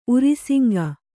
♪ urisiŋga